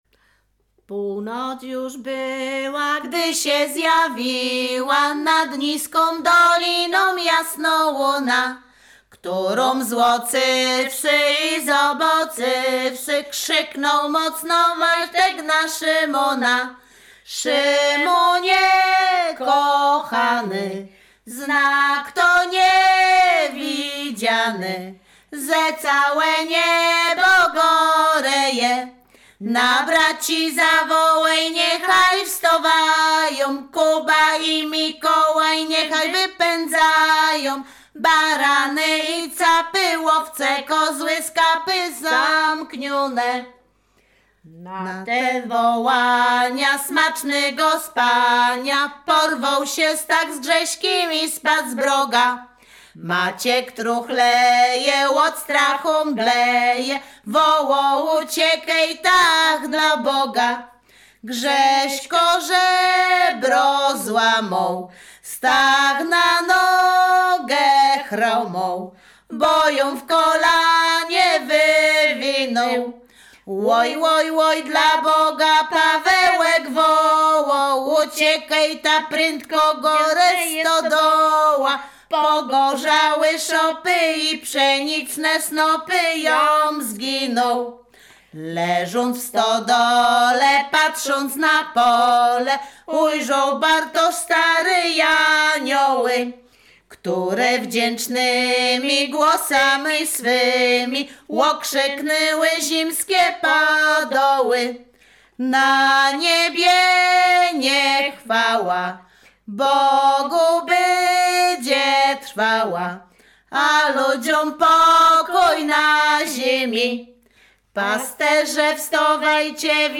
Śpiewaczki z Chojnego
województwo łódzkie, powiat sieradzki, gmina Sieradz, wieś Chojne
Pastorałka
Array kolędy kolędowanie zima bożonarodzeniowe pastorałki